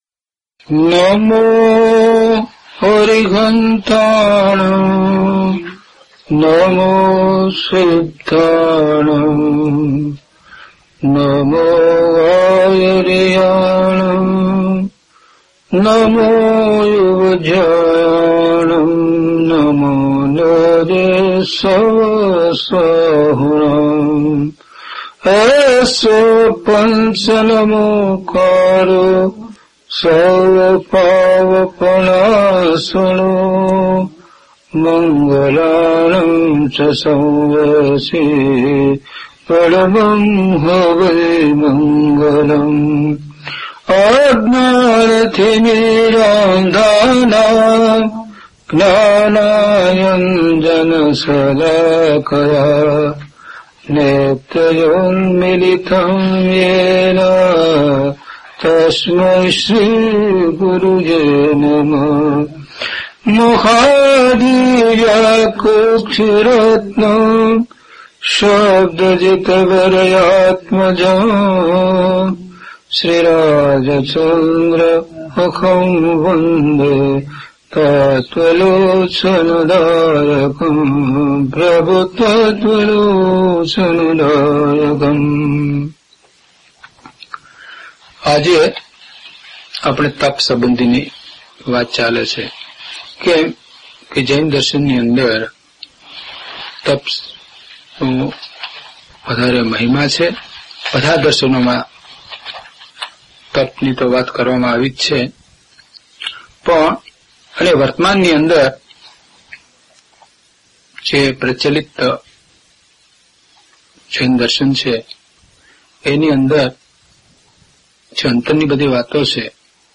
DHP042 Tap Swadhyay Ane Vinay - Pravachan.mp3